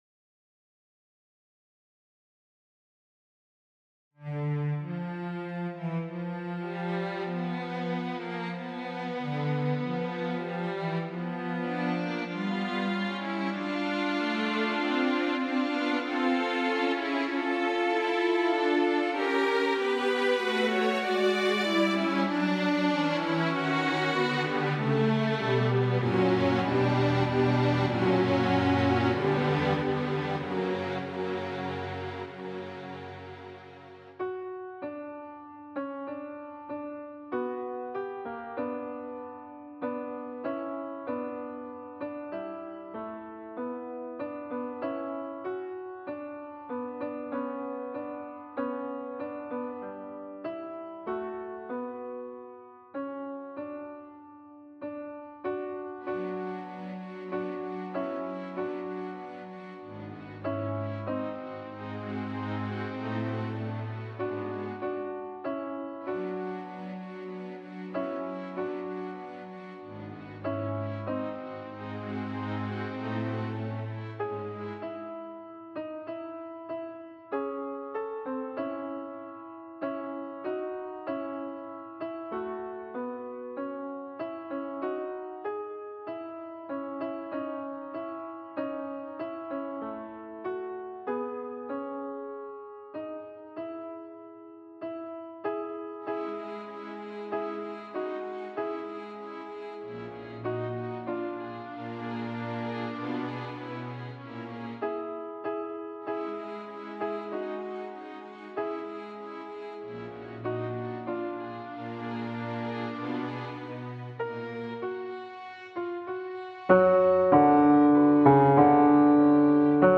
Bajo
Mp3 Música
2.-Offertoire-BAJO-Musica.mp3